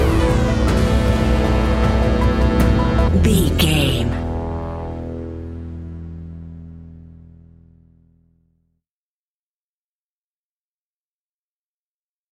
In-crescendo
Aeolian/Minor
scary
ominous
dark
haunting
eerie
synthesizer
ticking
electronic music
electronic instrumentals
Horror Synths